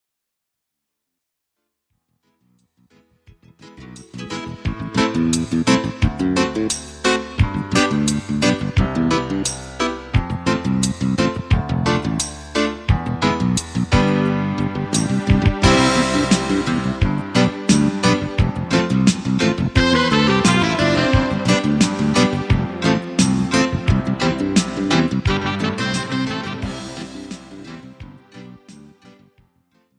This is an instrumental backing track cover.
• Key – Am
• Without Backing Vocals
• With Fade